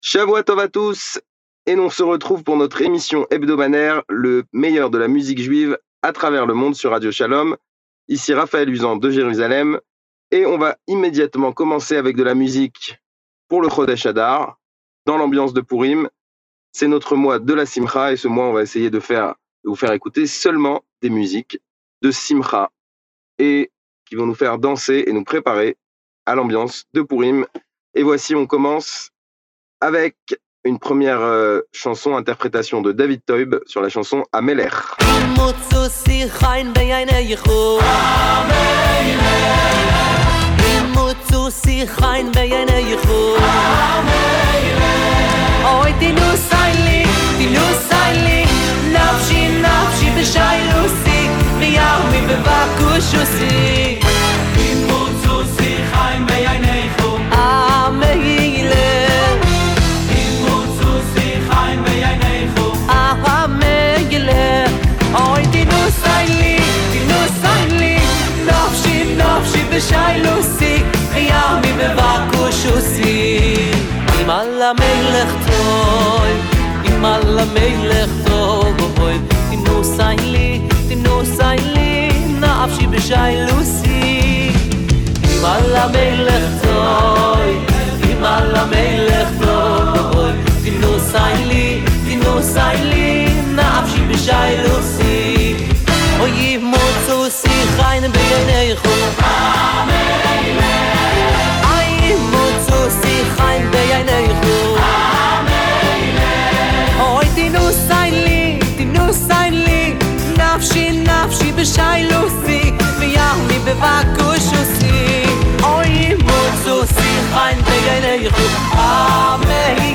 Le meilleur de la musique juive, tous les vendredis après-midi juste après Kabalat shabat, aux alentours de 17h20 !